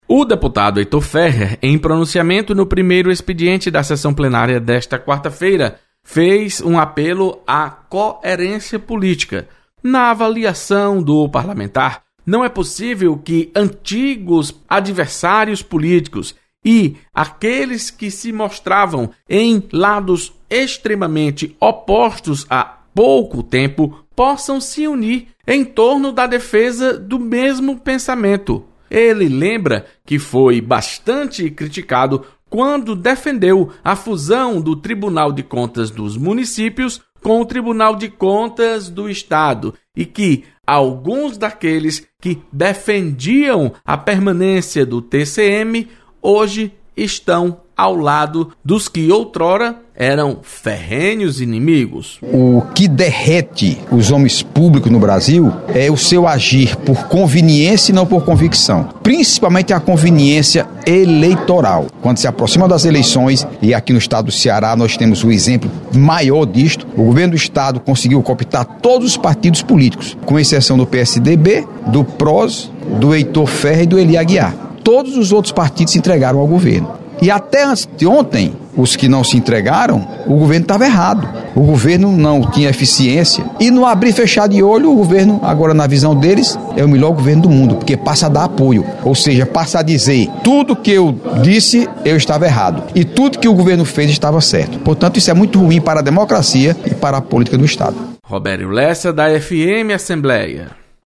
Deputado Heitor Férrer defende coerência na política. Repórter